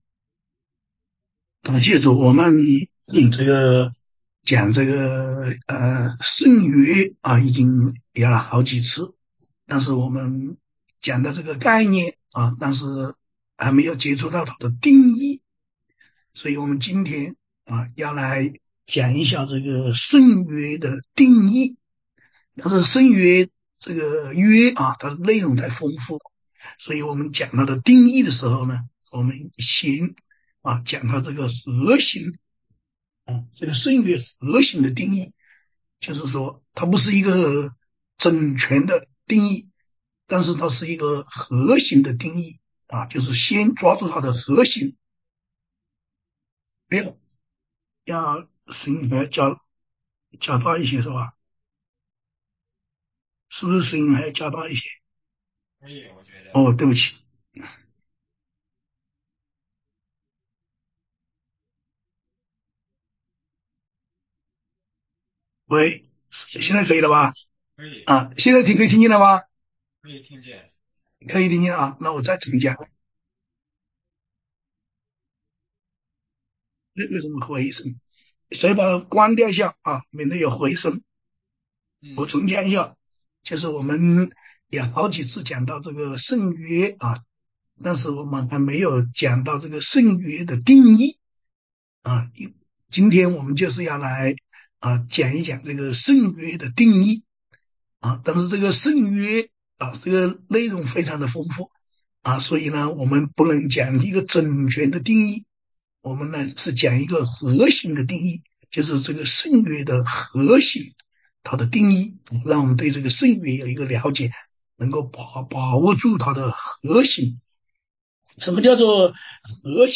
晨祷